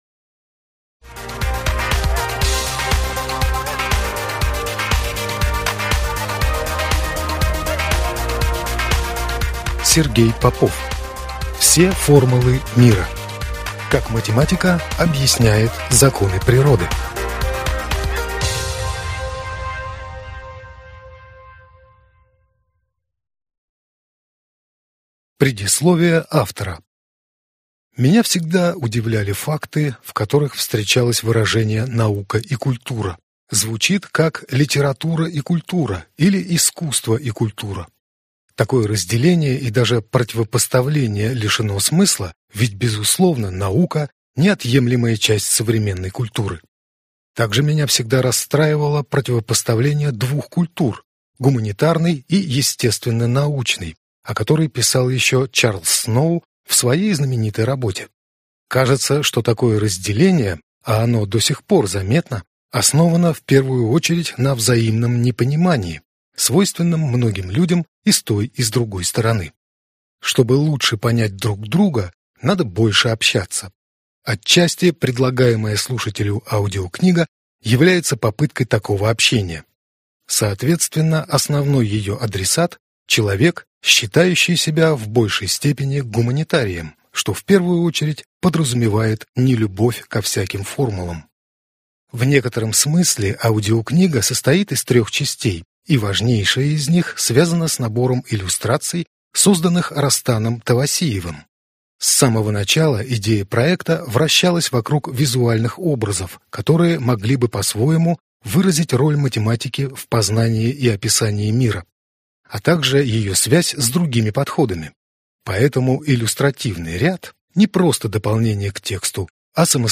Аудиокнига Все формулы мира | Библиотека аудиокниг